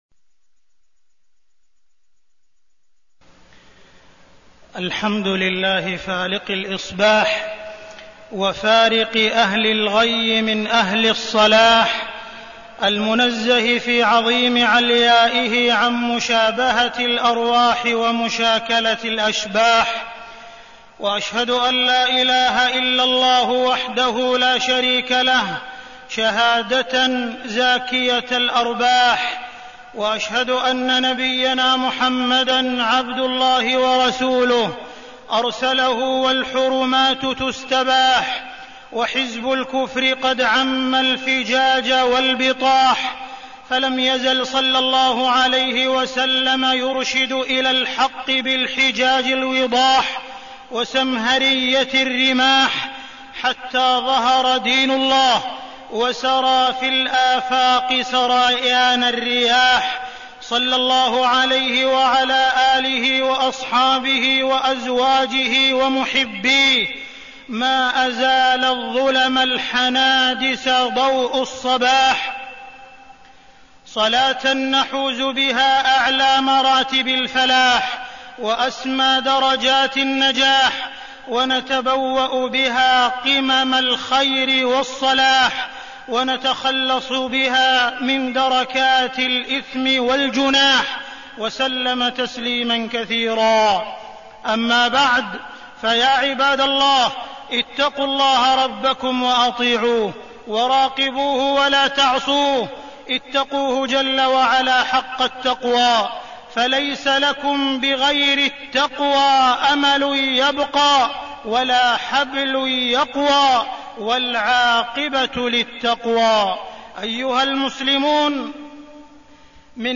تاريخ النشر ٥ جمادى الآخرة ١٤١٩ هـ المكان: المسجد الحرام الشيخ: معالي الشيخ أ.د. عبدالرحمن بن عبدالعزيز السديس معالي الشيخ أ.د. عبدالرحمن بن عبدالعزيز السديس القول على الله بغير علم